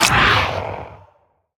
sounds / mob / phantom / bite2.ogg
bite2.ogg